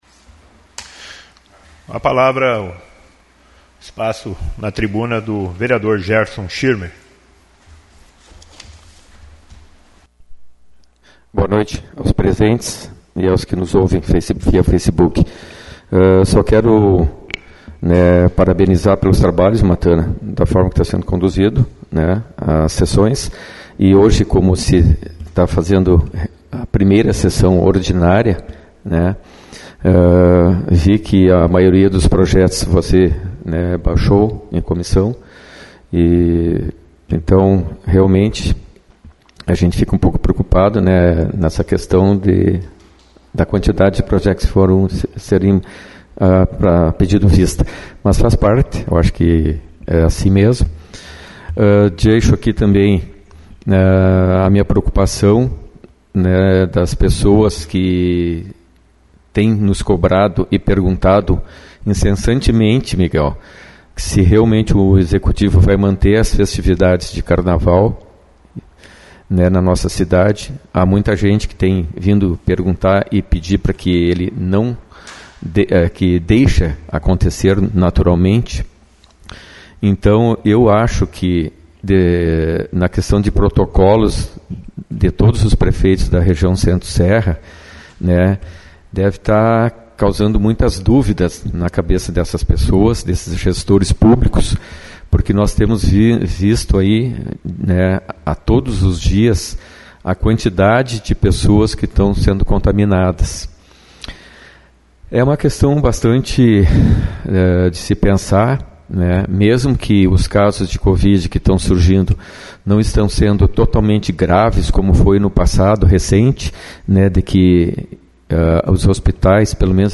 O Poder Legislativo de Sobradinho, promoveu nesta terça-feira (01), a primeira sessão ordinária do ano de 2022 sob a condução do presidente Jeferson Matana (PSB), na sala de sessões Ottmar Kessler da Câmara. O encontro ocorreu no final da tarde, onde os vereadores debateram e votaram três projetos de lei do Executivo, e baixaram para estudos em comissões outros quatro projetos.